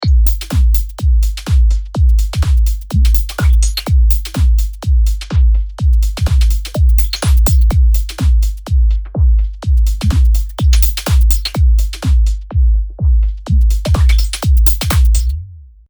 試しに、同じエフェクトを逆回転でかけてみましょうか。（笑）
※ 試聴注意：低域がヤバめです。音量に気をつけて！